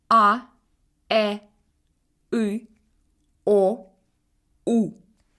1.Si la consonne est suivie d'une voyelle de première série :[1] (а, э, ы, о, у), on dit qu'elle est dure.